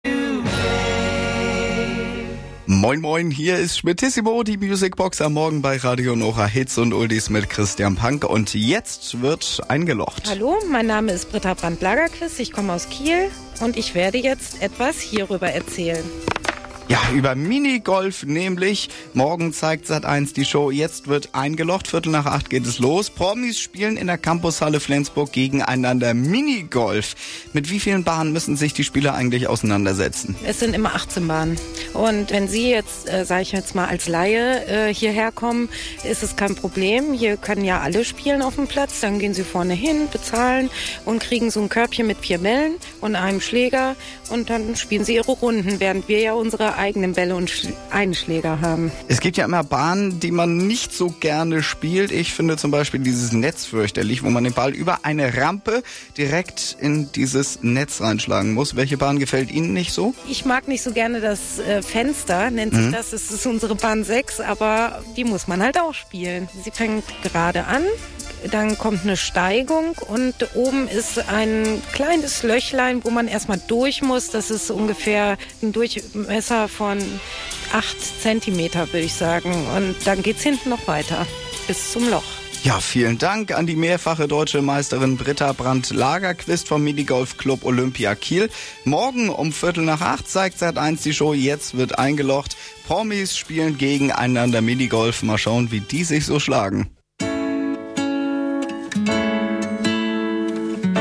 Einen Tag vor der "Sat.1" - Show "Jetzt wird eingelocht!" riefen viele große Rundfunkanstalten Minigolfer ihrer Region an, fragten sie zur Ausstrahlung der Sendung und zum Thema Minigolf allgemein.